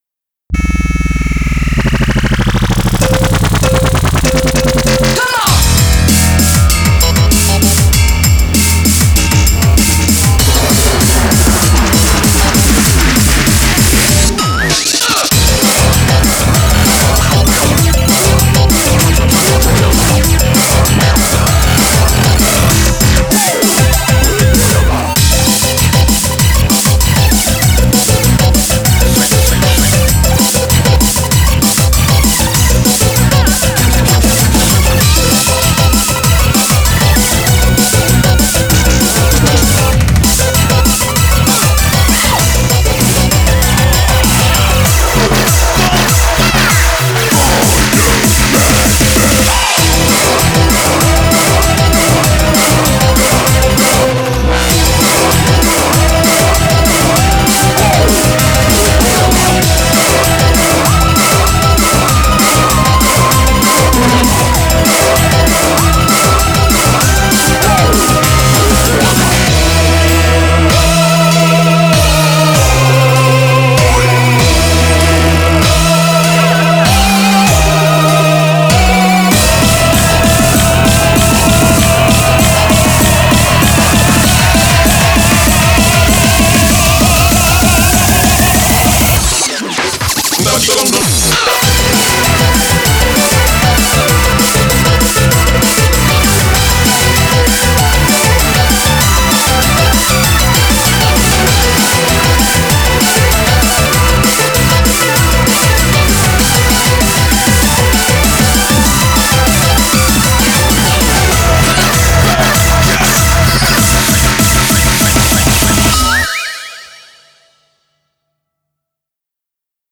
BPM195
Audio QualityPerfect (High Quality)
Comments[FUNKOT]